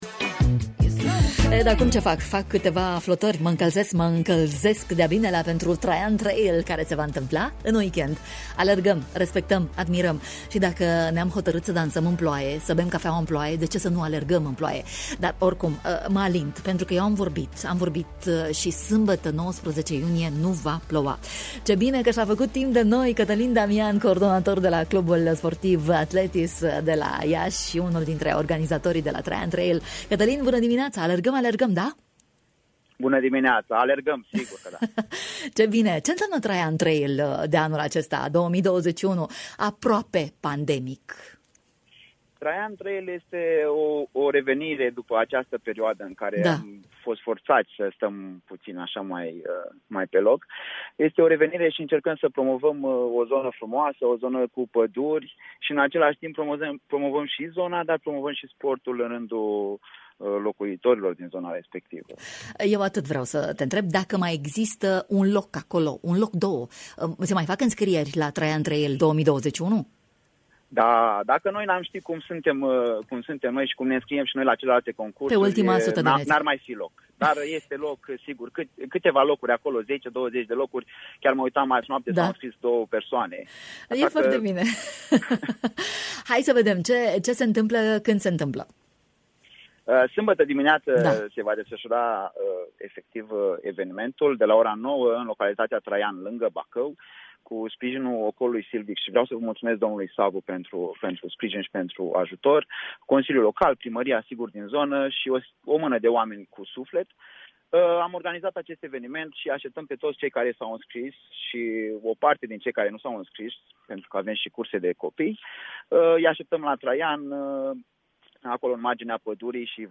a dat o tură de „radio” în matinal: